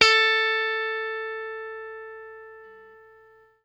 FENDRPLUCKAM.wav